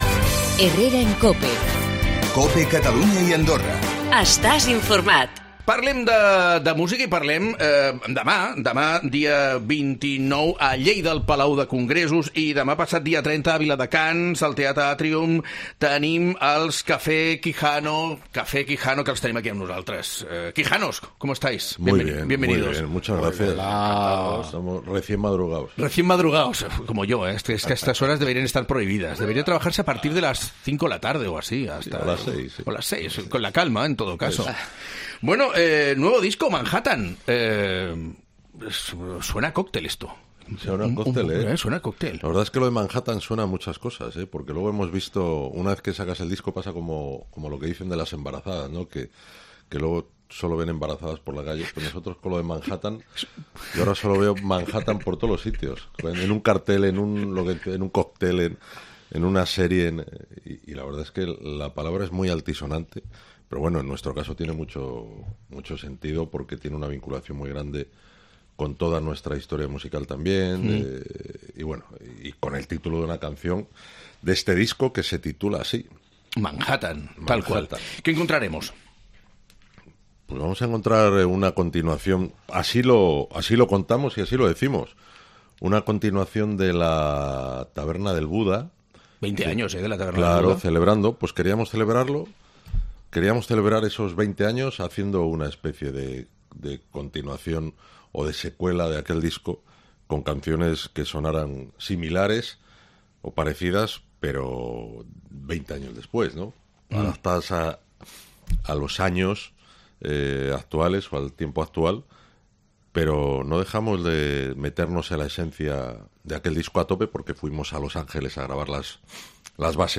Els hem convidat al programa d'avui perquè ens donin més detalls sobre el seu nou disc